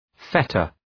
Προφορά
{‘fetər}